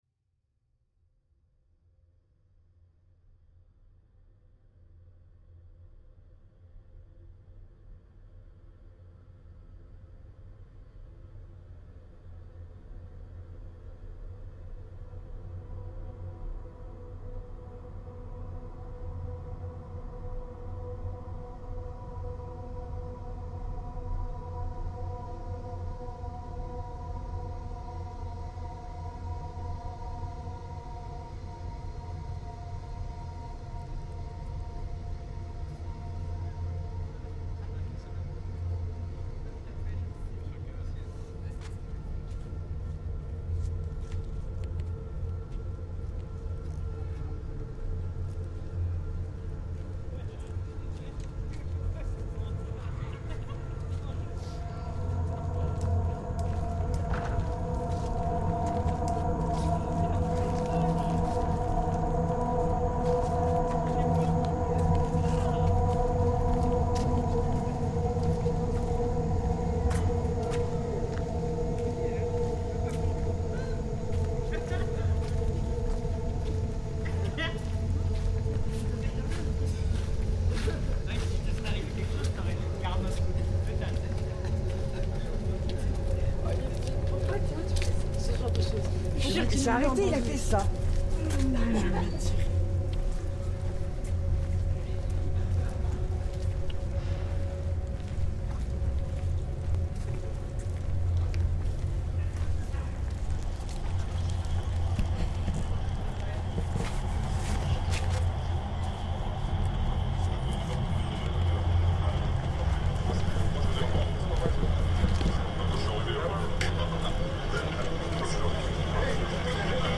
Pere Lachaise cemetery reimagined